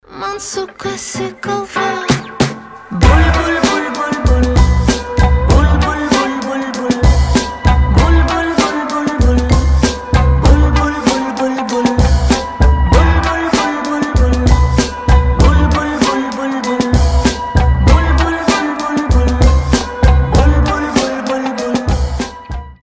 • Качество: 320, Stereo
позитивные
милые
смешные